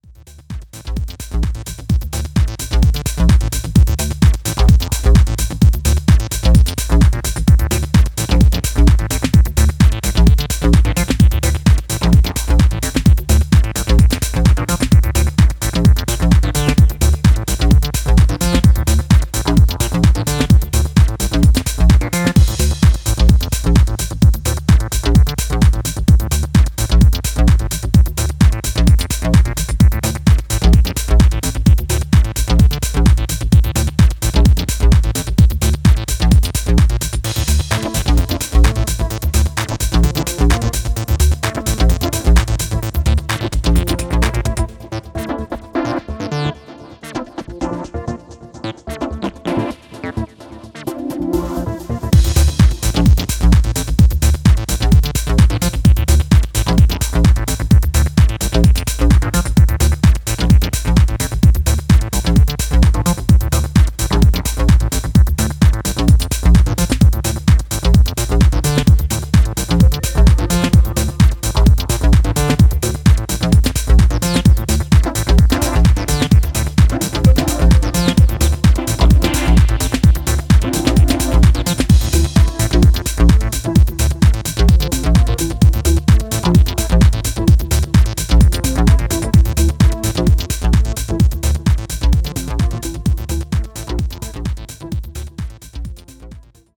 必要十分な構成要素のいぶし銀ミニマル・アシッド・ハウス